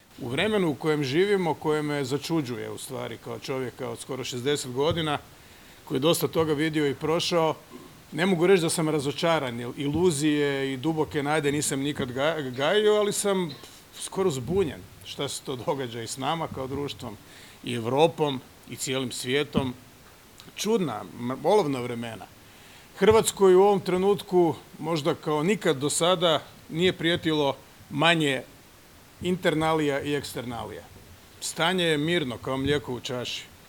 Koliko je politologija važna u vremenu u kojemu živimo, svjestan je i predsjednik Zoran Milanović, koji se na svečanosti obilježavanja Dana Fakulteta političkih znanosti osvrnuo na trenutnu aktualnu situaciju u zemlji i svijetu.